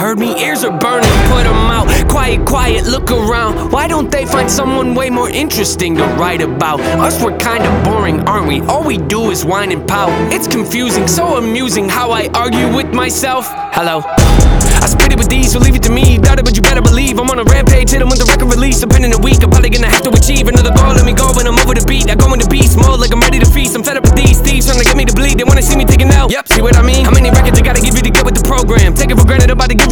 Genre: Hip-Hop/Rap